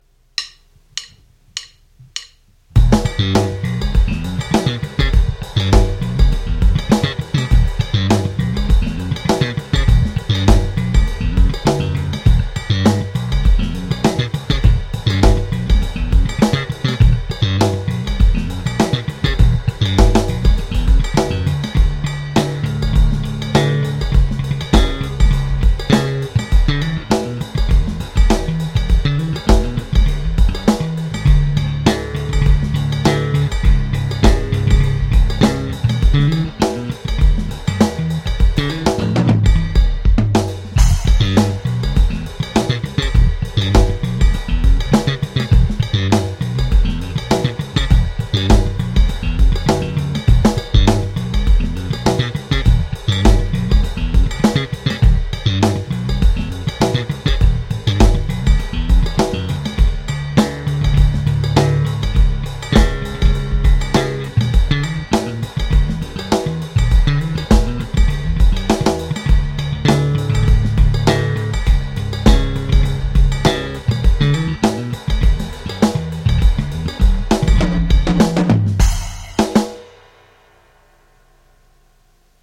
Live Machine Gun Style Slap Bass Groove AKA Mark King Level 42 Along To The Funk Drum Iphone App
You can't beat a set of new stings on ya bass :)